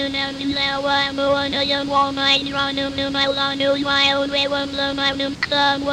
I actually use an audio except from their NETtalk demonstration to show how the machine learning algorithm changes from prattling nonsense to correctly reading the English text in a training set.
nonsense:
nettalk.01.wav